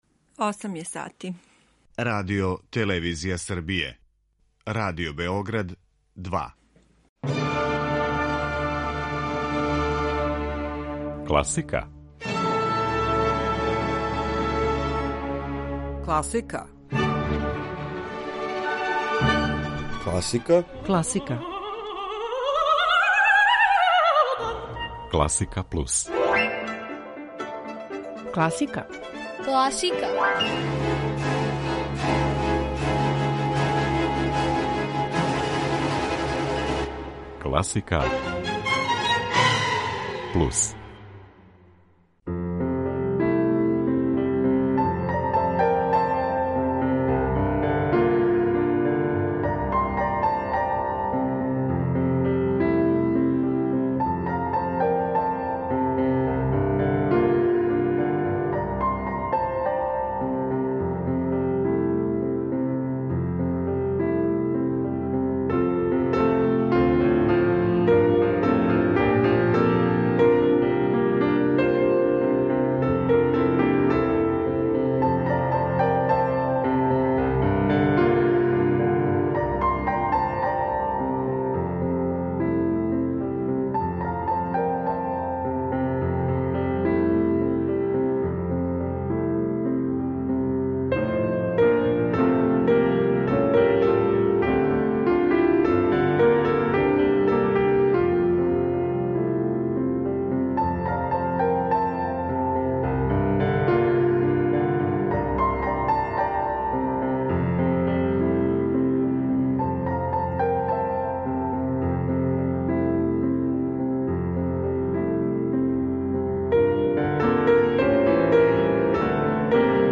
Емисија класичне музике
А њихов је репертоар занимљив и разноврстан.